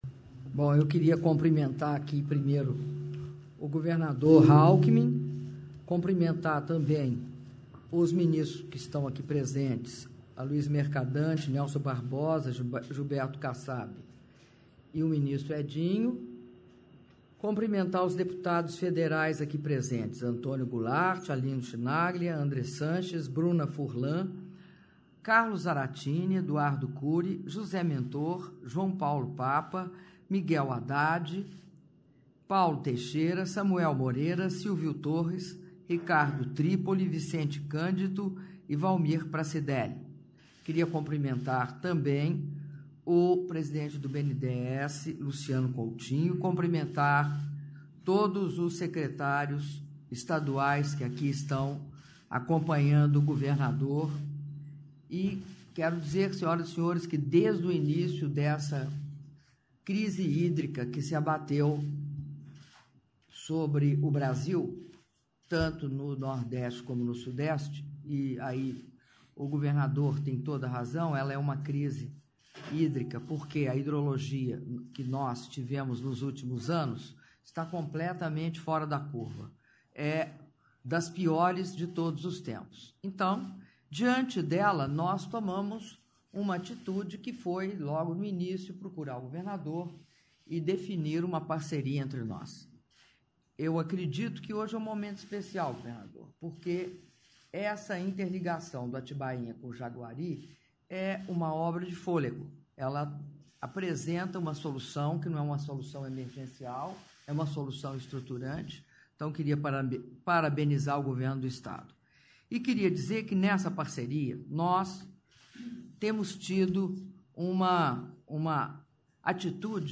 Áudio da declaração da Presidenta da República, Dilma Rousseff, durante assinatura de contrato entre a Sabesp e o BNDES - Brasília/DF (05min17s)